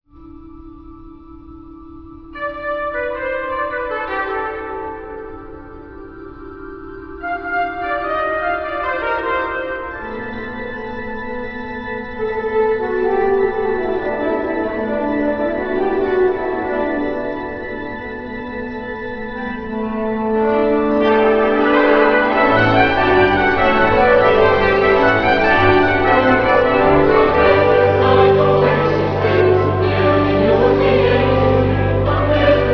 tenor
organ.